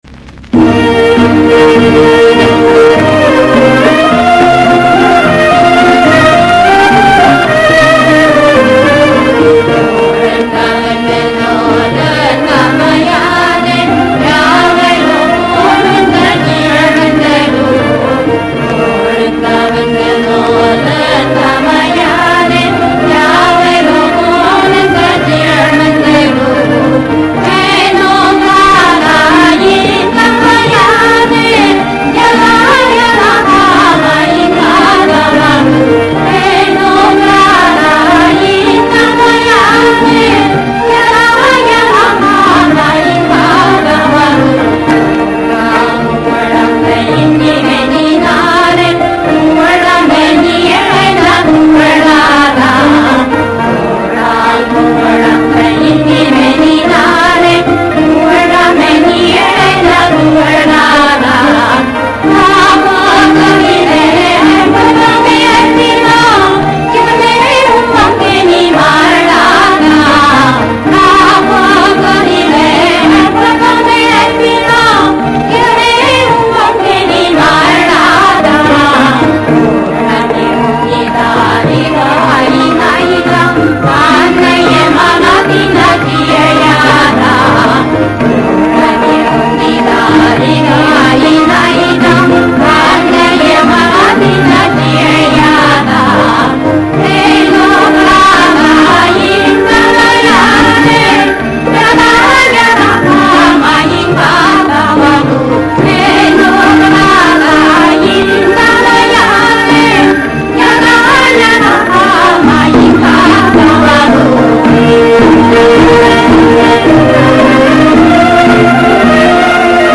我上传的这个是从薄膜唱片转制的，所以音质很差，哪位老师有就帮忙发上来，连接也行。